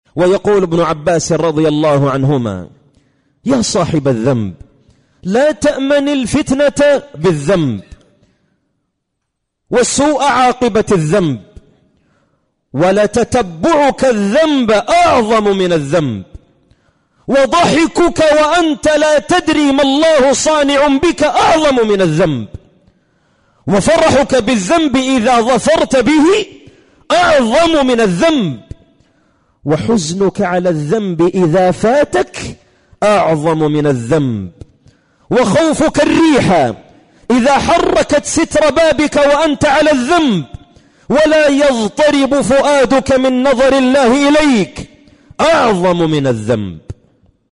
مقطع من خطبة.